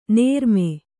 ♪ nērme